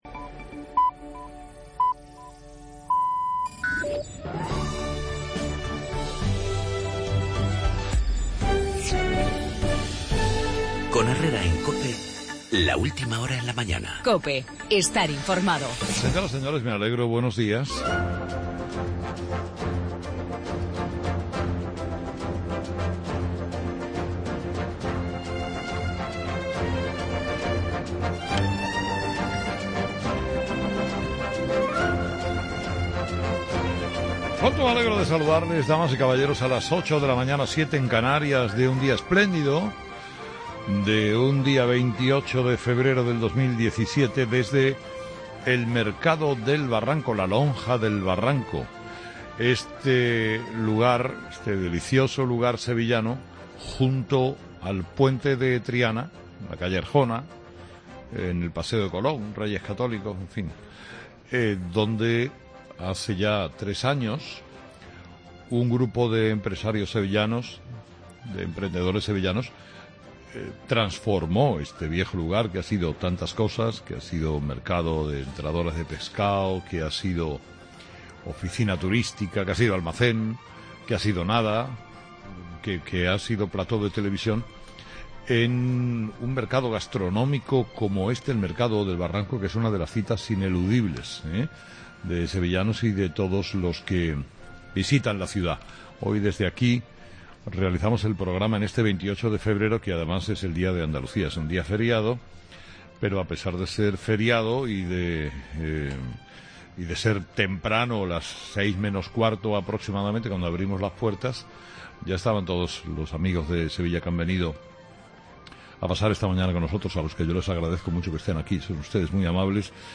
Monólogo de las 8 de Herrera